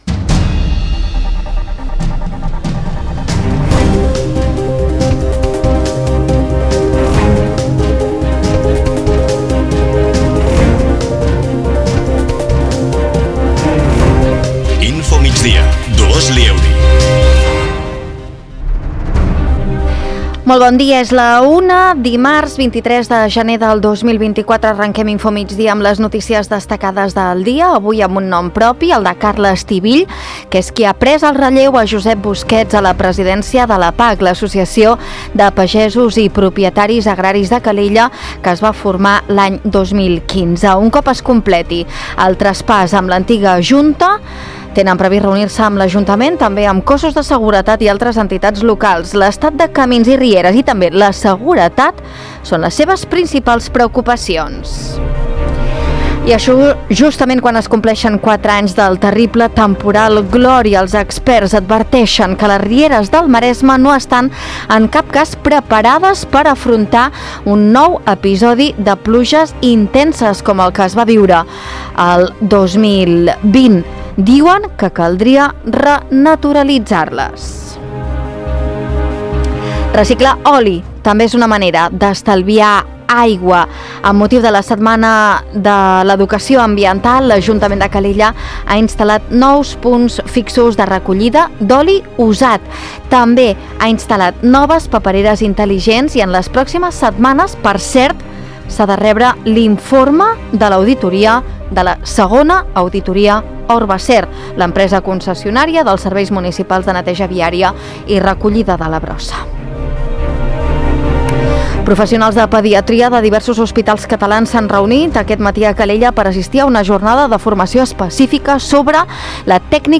Notícies d’actualitat local i comarcal.